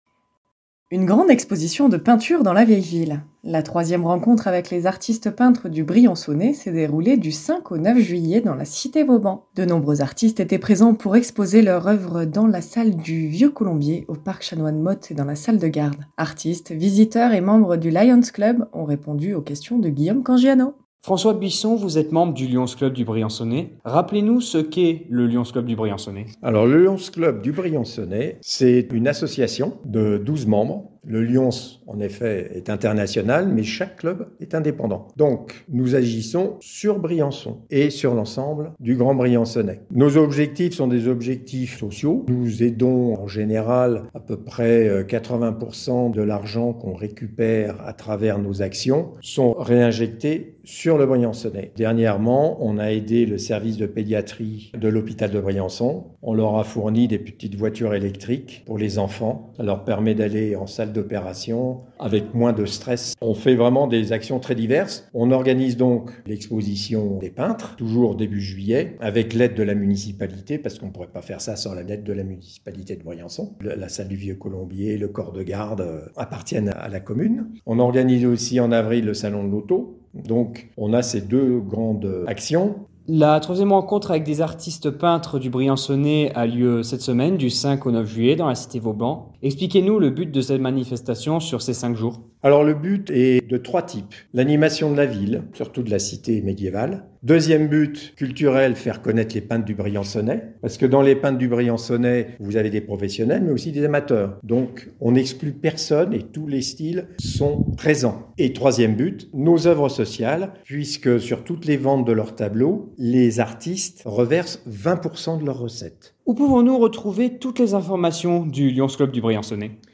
3EME RENCONTRE EXPOSITION REPORTAGE FINIE.mp3 (5.39 Mo)